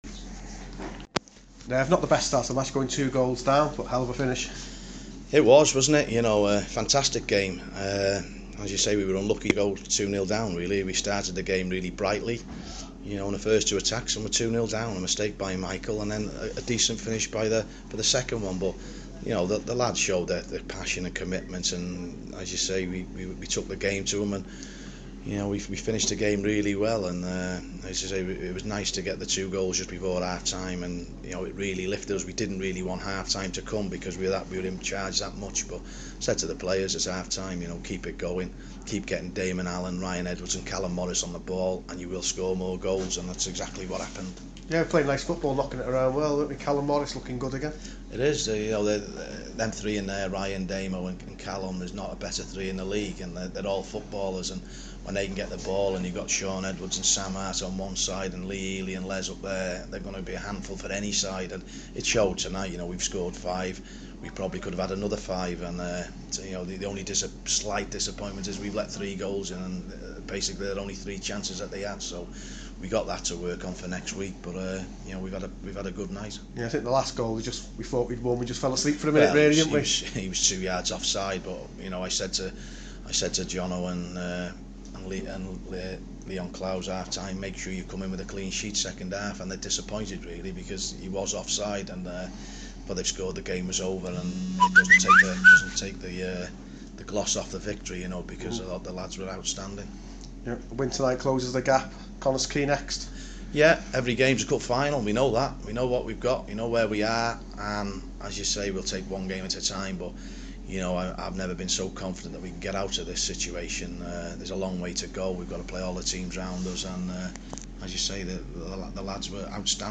interview after 5-3 win over Cefn Druids